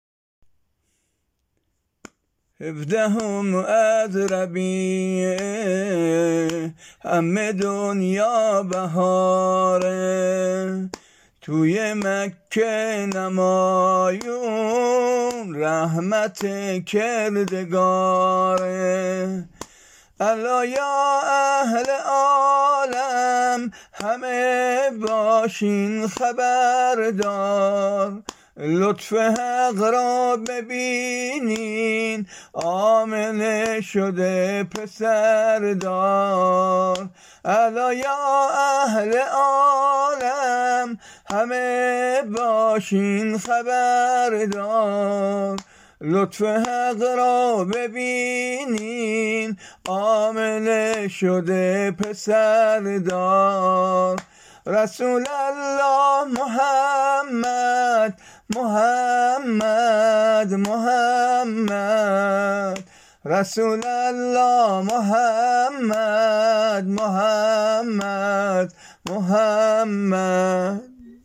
سرود میلاد حضرت محمد(ص)